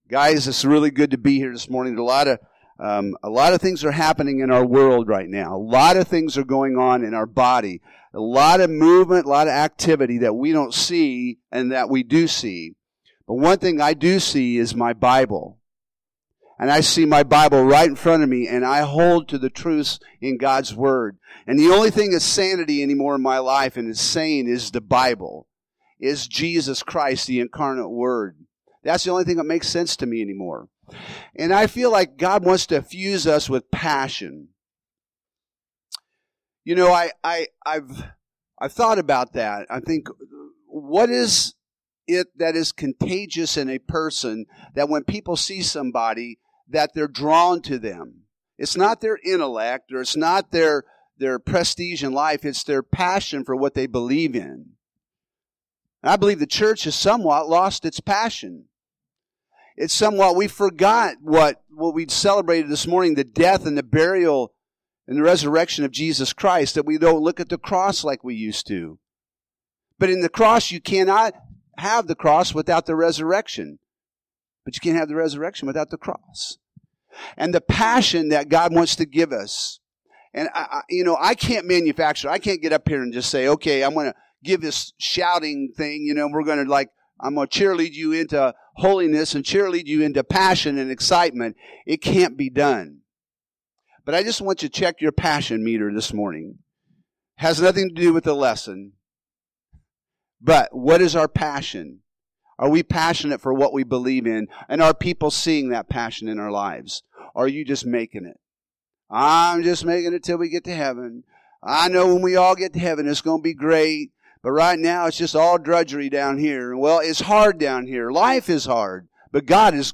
Home › Sermons › Ephesians 1:4~15